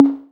{Perc} pick up 5.wav